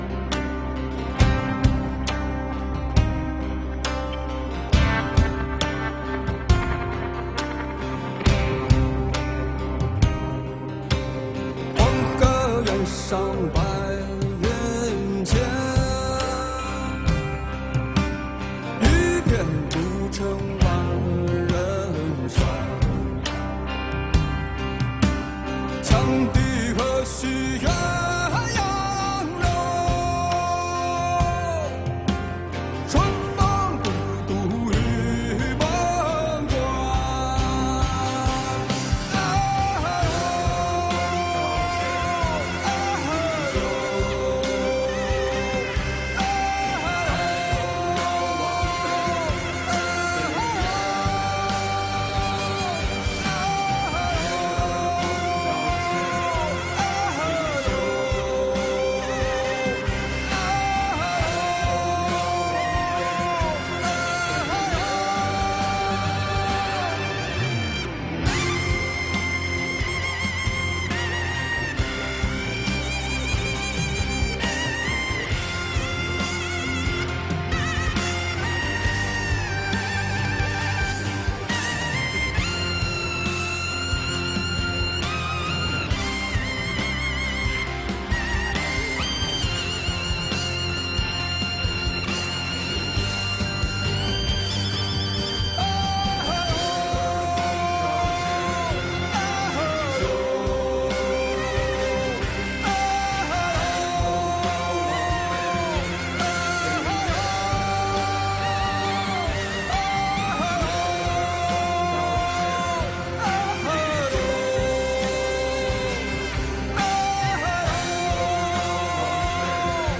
chant_涼州詞.mp3